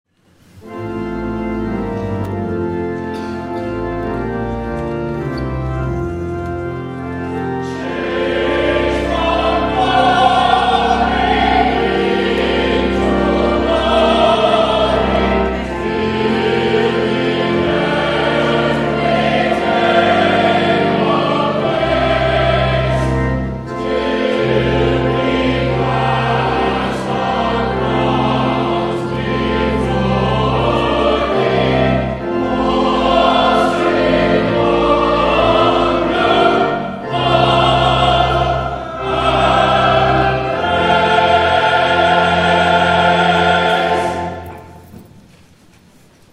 *THE PROCESSIONAL HYMN 376